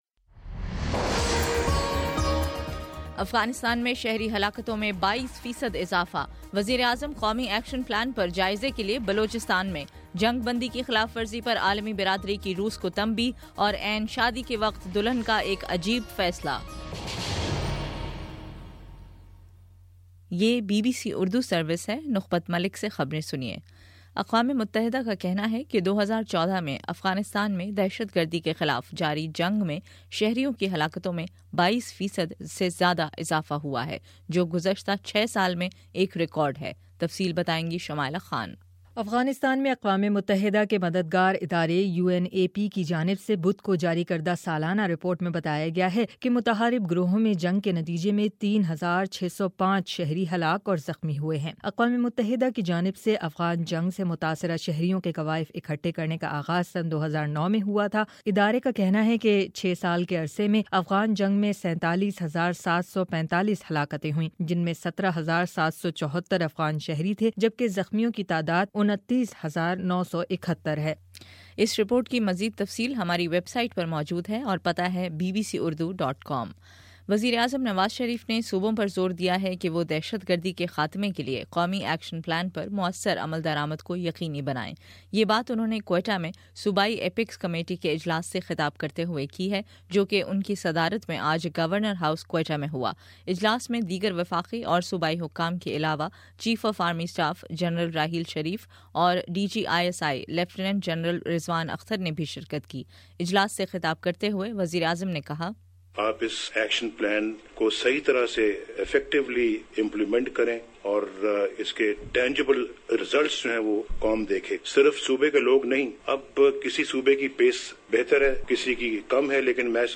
فروری 18: شام چھ بجے کا نیوز بُلیٹن